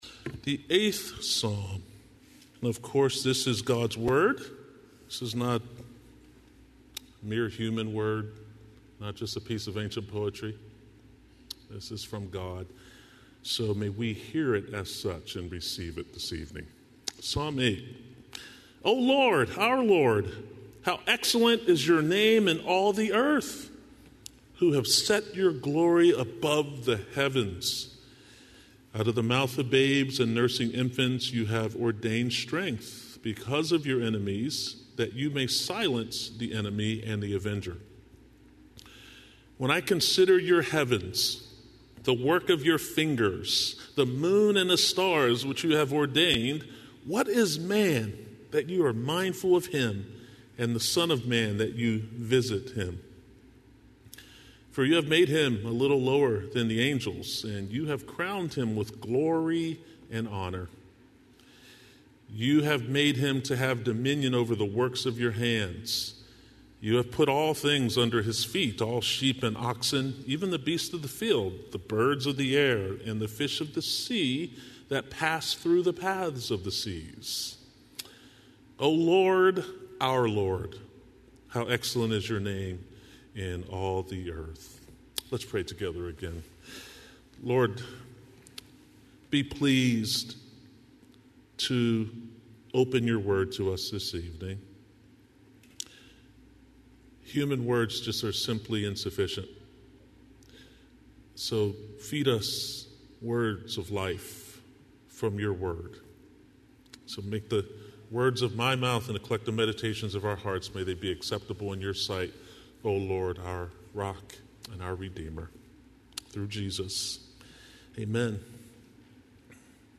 00:00 Download Copy link Sermon Text Psalm 8